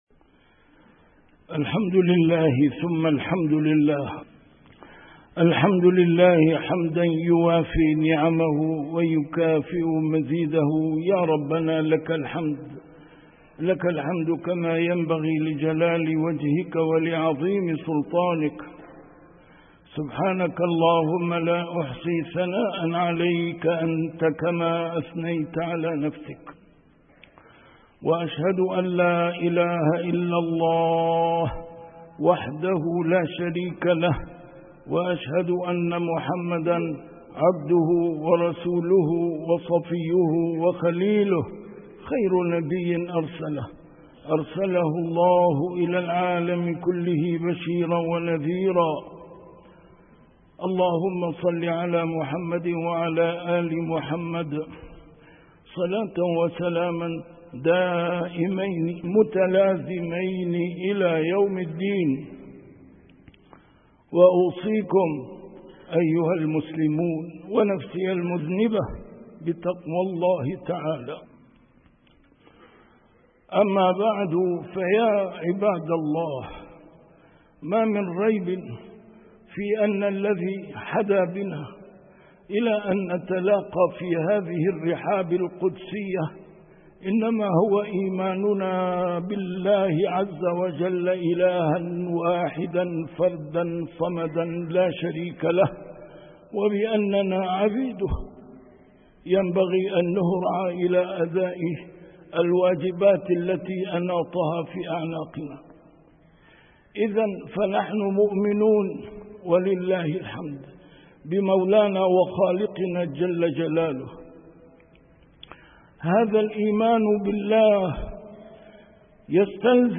A MARTYR SCHOLAR: IMAM MUHAMMAD SAEED RAMADAN AL-BOUTI - الخطب - وَلَيَنصُرَنَّ اللَّهُ مَن يَنصُرُهُ